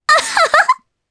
Epis-Vox_Happy3_jp.wav